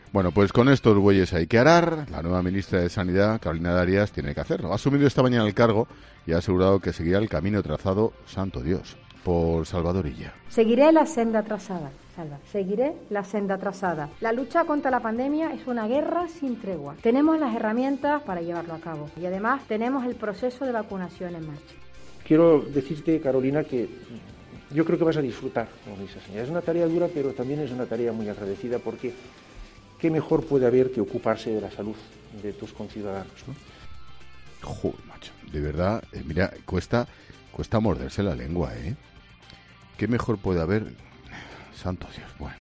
El traspaso de cartera en el Ministerio de Sanidad ha motivado unas declaraciones del ministro saliente que enervan al presentador de 'La Linterna'
Ante tal afirmación, Expósito no ha contenido su hartazgo.